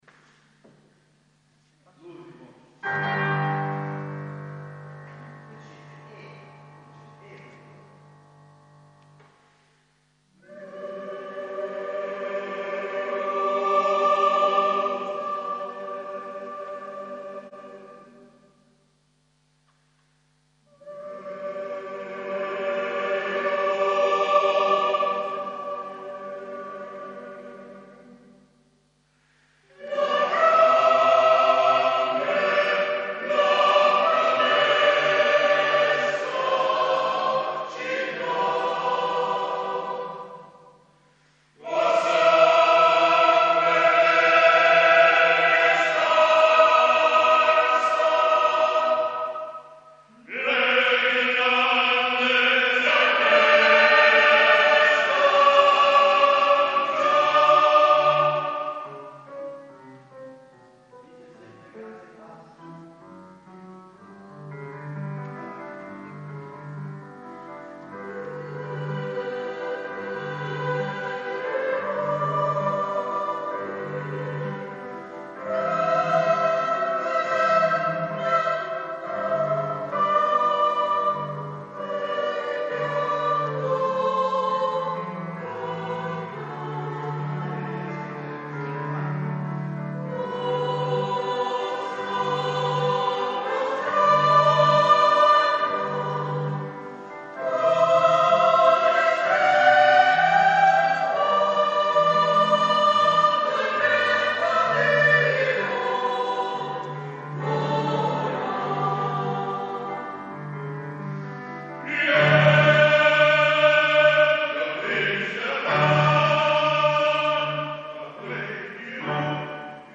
Coro Polifonico Beato Jacopo da Varagine - Varazze